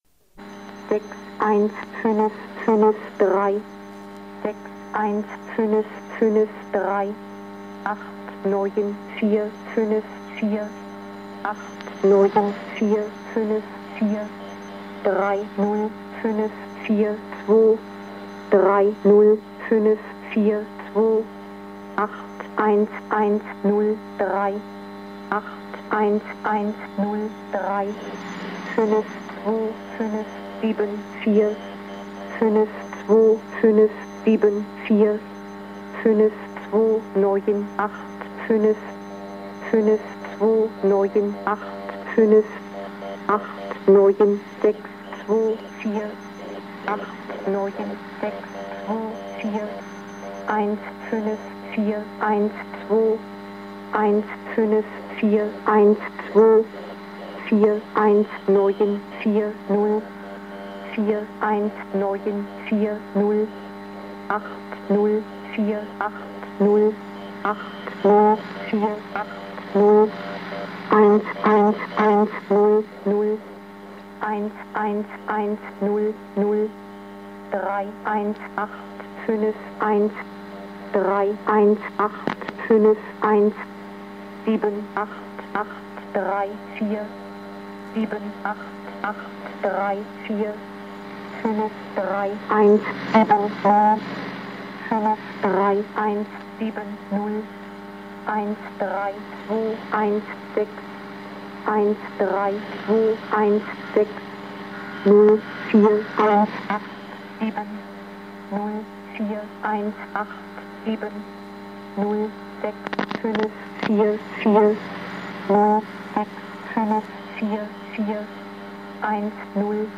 11:00 Mode: USB Frequency
Recorded on tape on 1988.
B2_Zahlensender.mp3